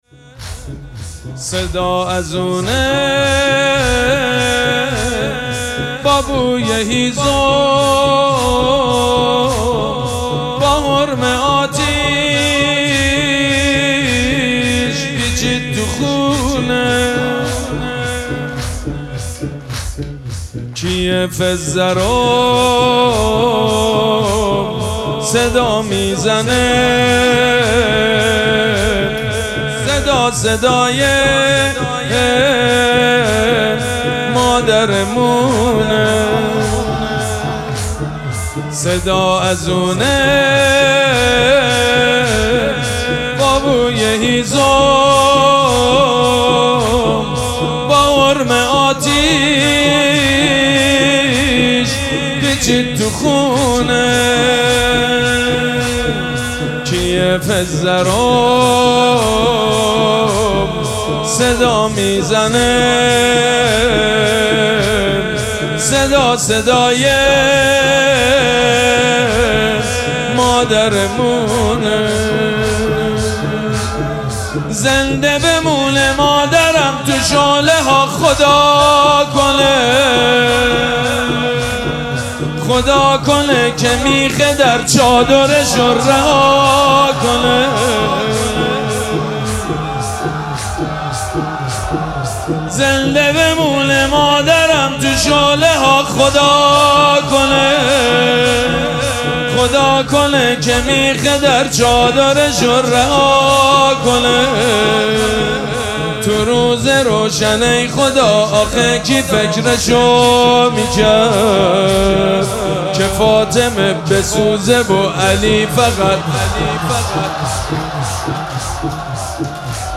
شب سوم مراسم عزاداری دهه دوم فاطمیه ۱۴۴۶
مداح
حاج سید مجید بنی فاطمه